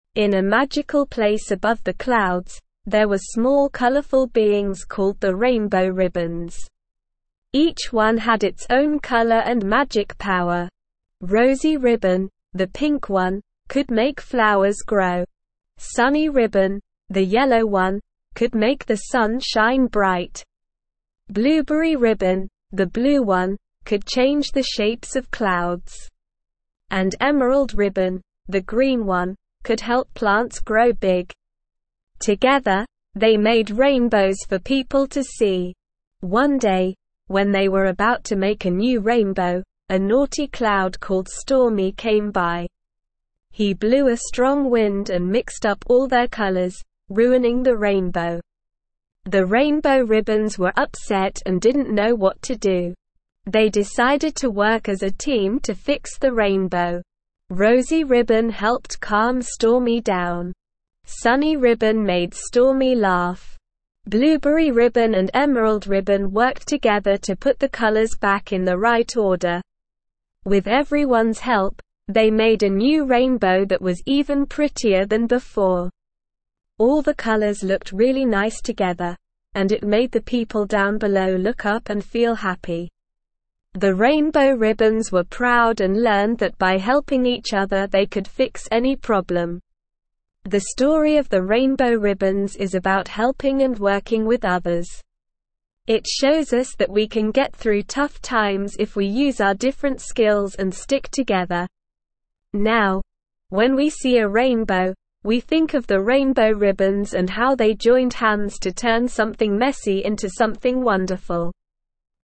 Slow
ESL-Short-Stories-for-Kids-Lower-Intermediate-SLOW-Reading-Rainbow-Ribbons-in-the-Sky.mp3